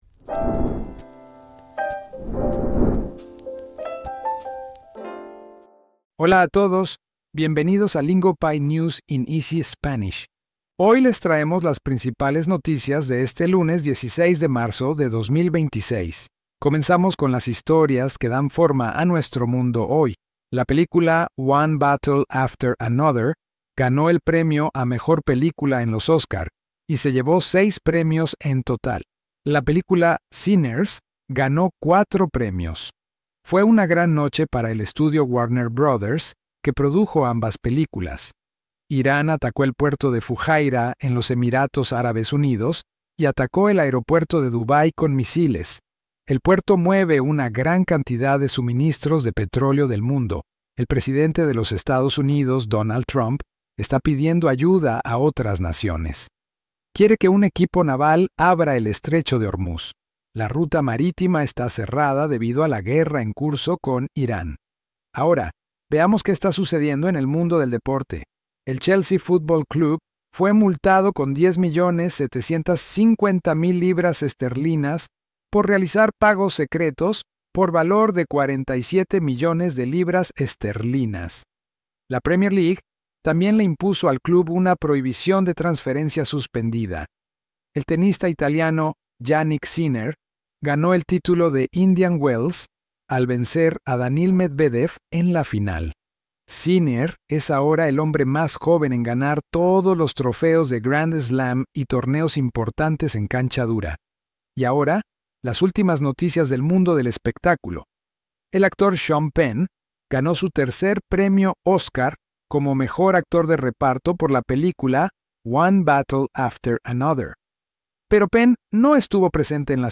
This episode breaks down the biggest stories in slow, clear Spanish so you can focus on understanding, not guessing.